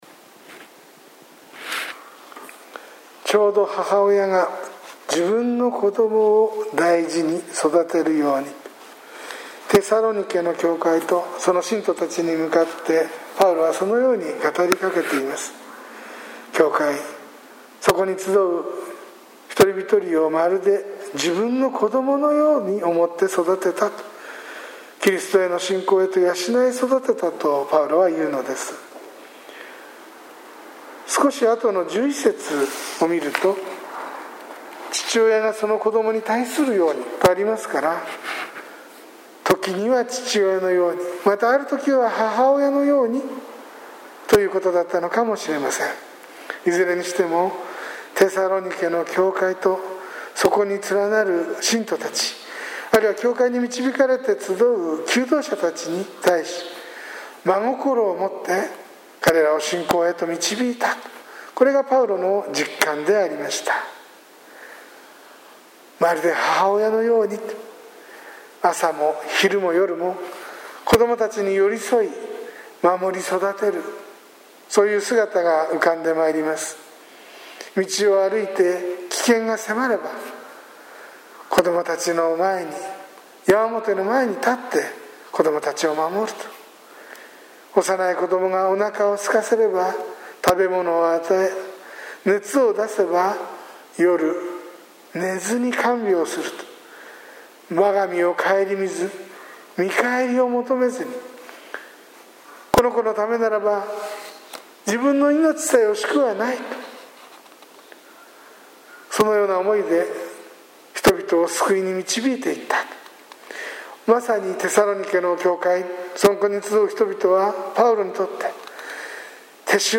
sermon2020-05-17